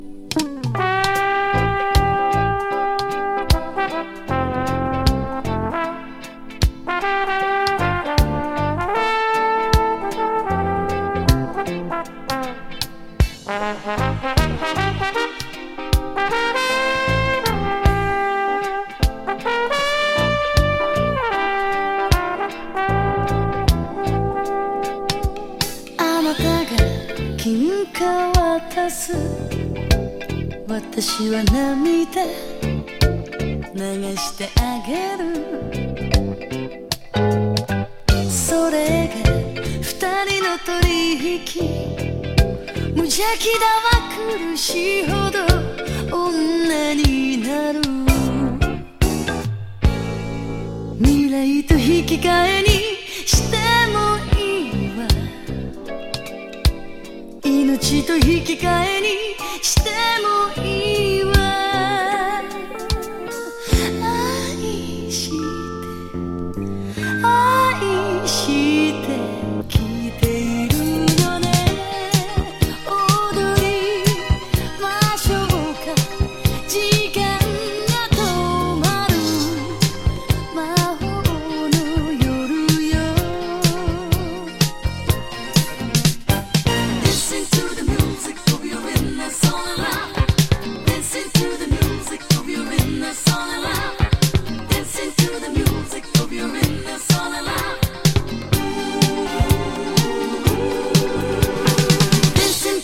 メロウ・ラヴァーズ
ディスコ